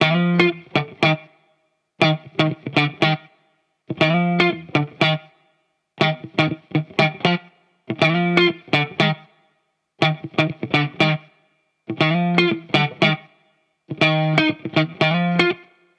BO_FAF_120_Electric_Guitar_Loop_Second_Hip_F.wav